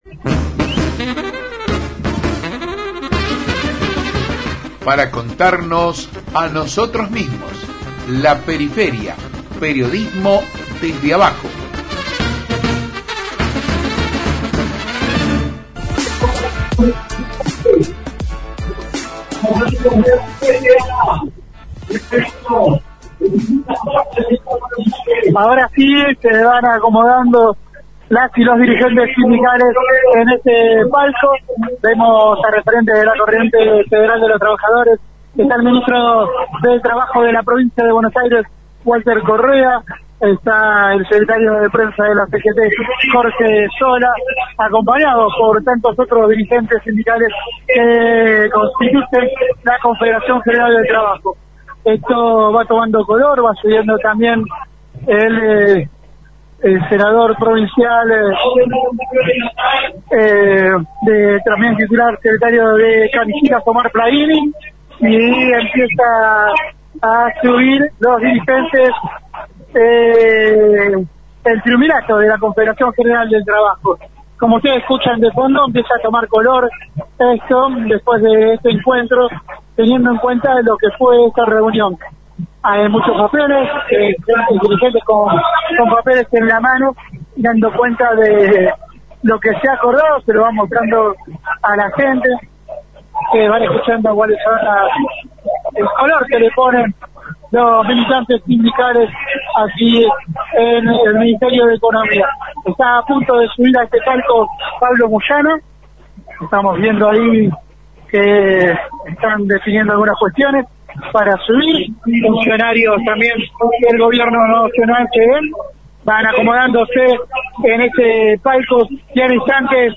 02-DISCURSO-MASSA.mp3